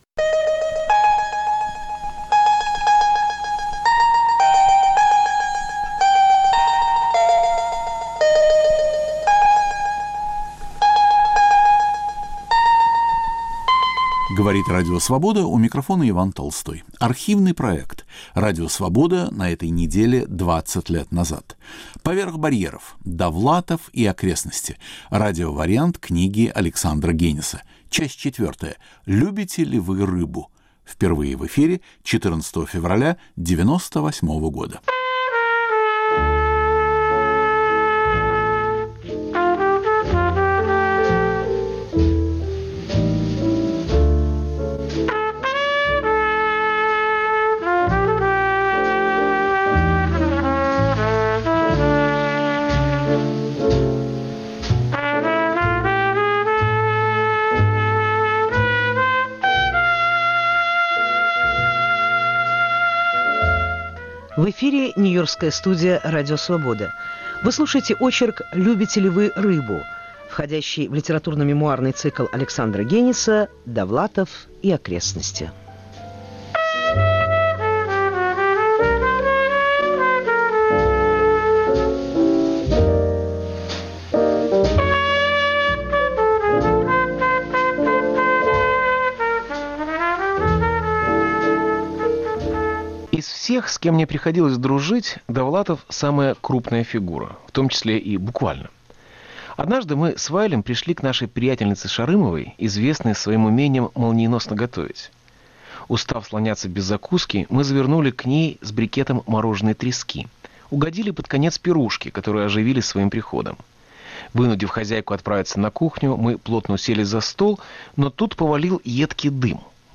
Радиовариант книги Александра Гениса читает автор.